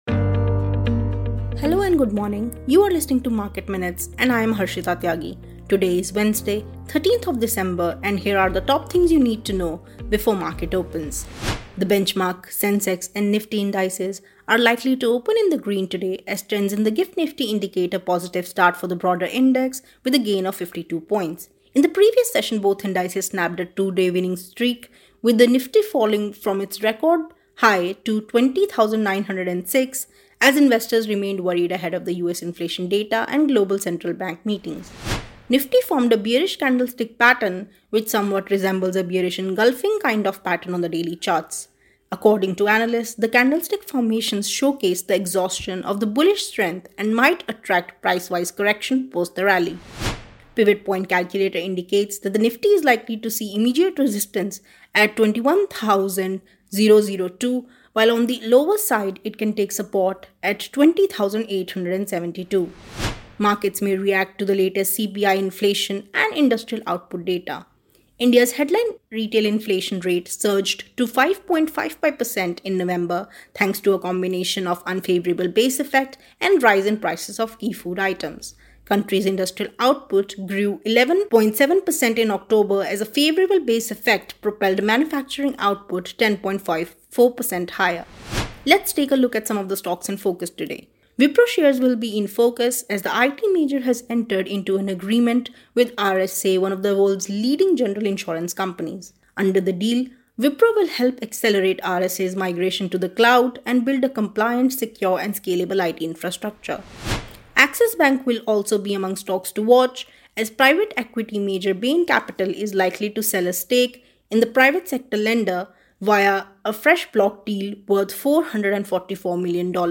Market Minutes is a morning podcast that puts the spotlight on hot stocks, key data points, and developing trends.